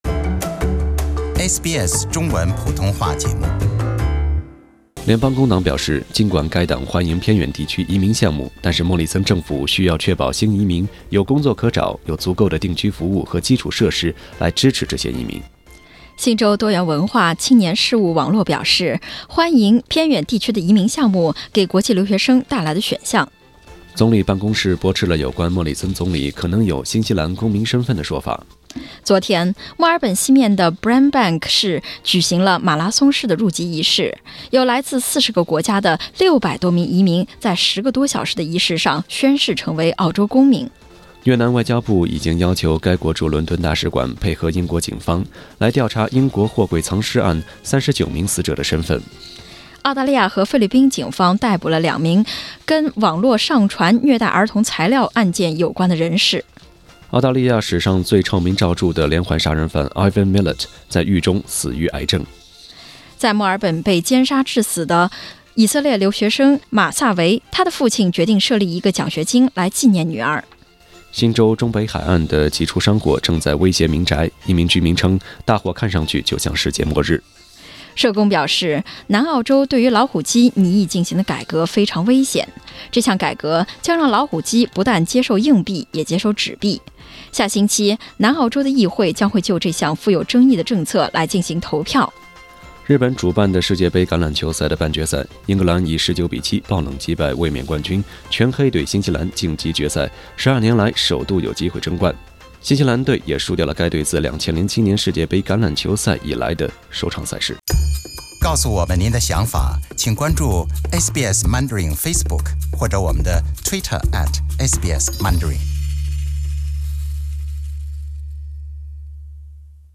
SBS早新闻（10月27日）